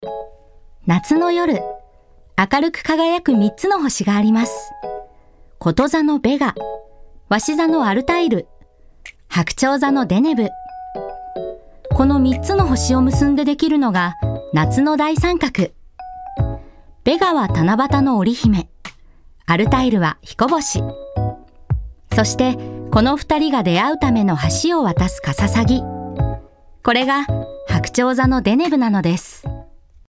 声を聴く Voice Sample
5.ナチュラルに